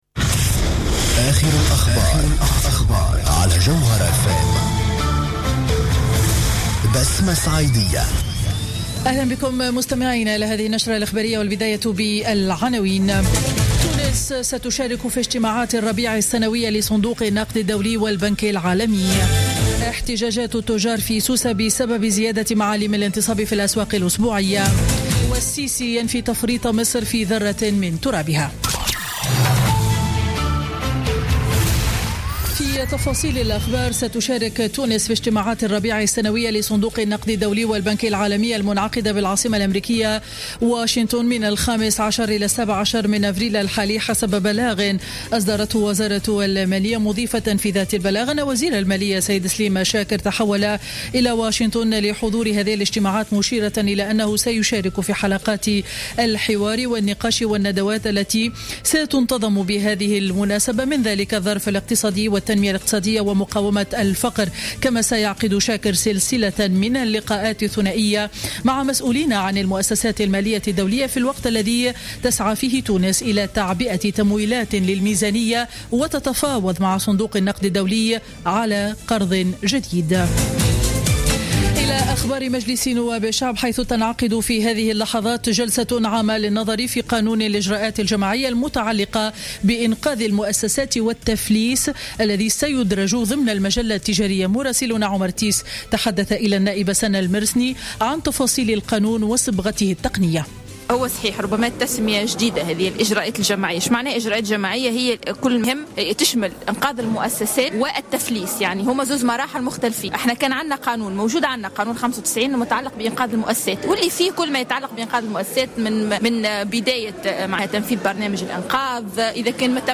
Journal Info 12h00 du mercredi 13 avril 2016